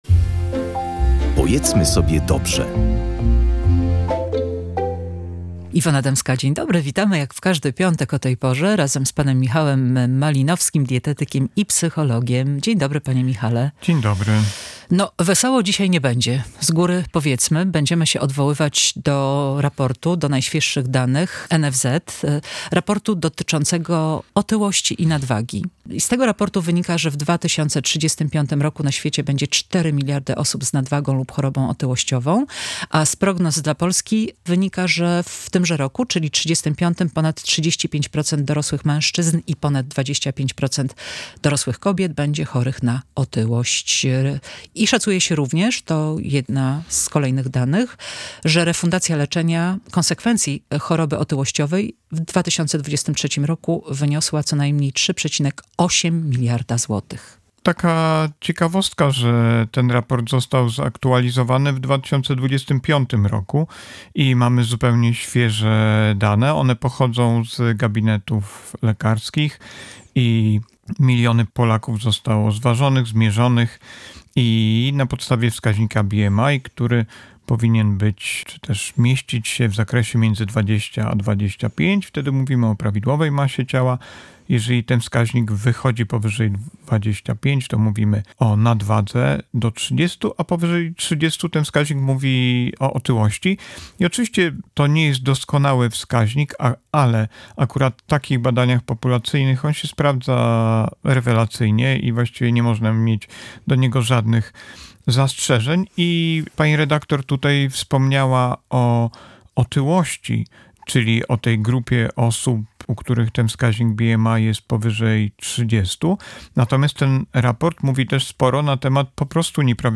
rozmawia z dietetykiem i psychologiem